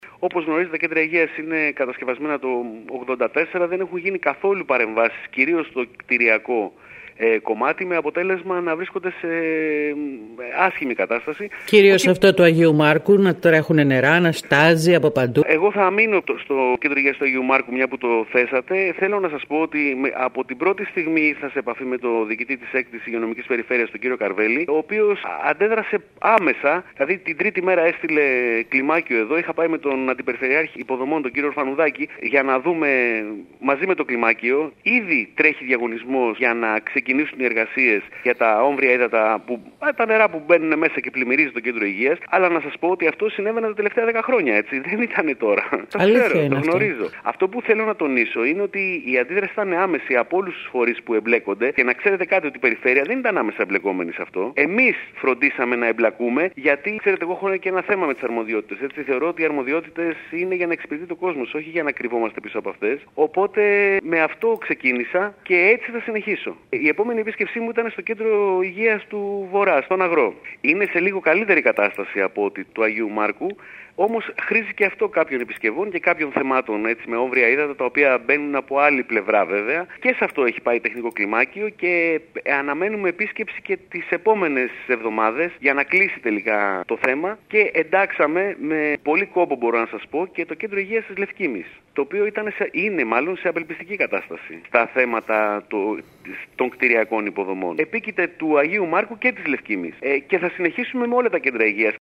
Έχουν ξεκινήσει οι διαδικασίες του διαγωνισμού προκειμένου να ανακηρυχθεί εργολάβος ο οποίος θα επισκευάσει το Κέντρο Υγείας του Αγίου Μάρκου, δήλωσε μιλώντας σήμερα στην ΕΡΑ ΚΕΡΚΥΡΑΣ ο αντιπεριφερειάρχης αρμόδιος για θέματα υγείας και κοινωνικής πολιτικής Κώστας Ζορμπάς.